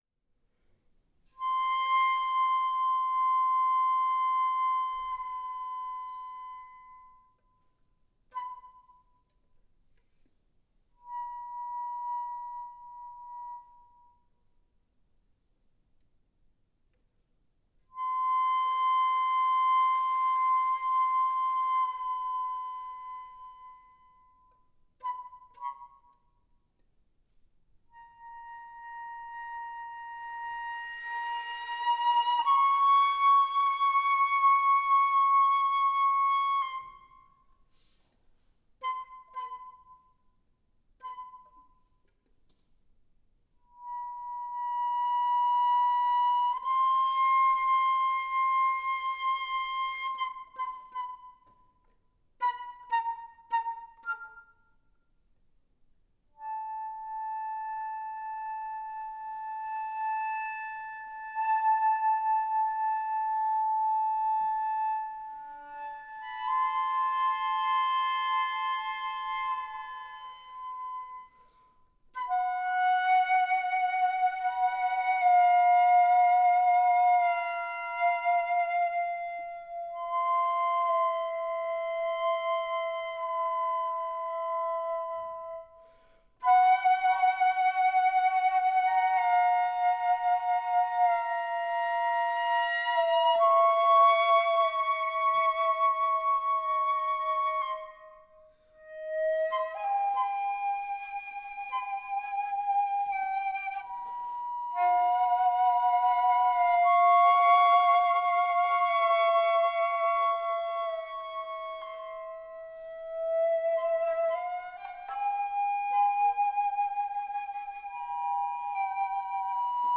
…one gallon of tears… (two flutes)
Venue: University of Maryland, College Park